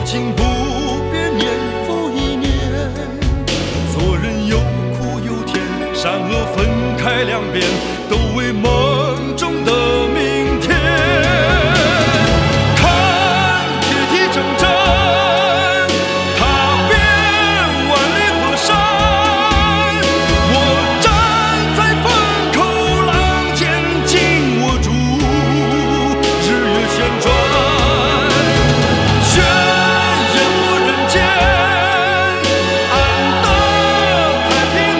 Жанр: Поп
# Mandopop